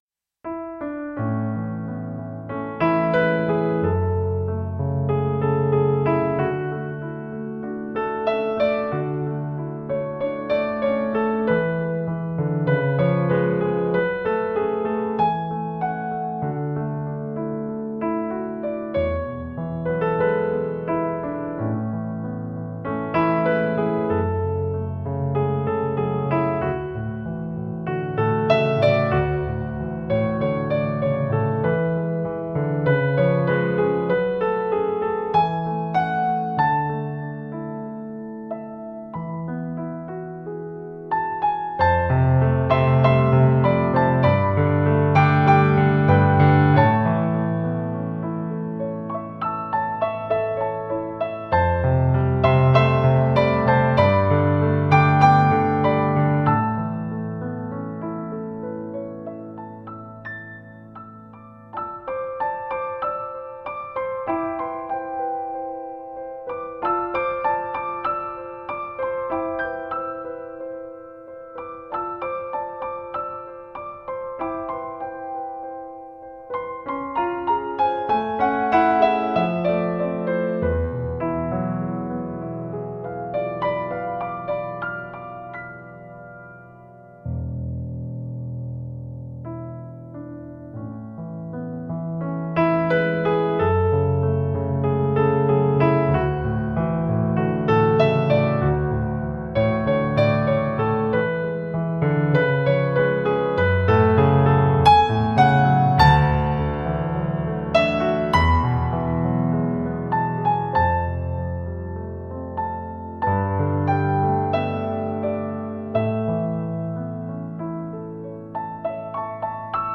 ピアノソロ